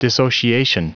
Prononciation du mot dissociation en anglais (fichier audio)
Prononciation du mot : dissociation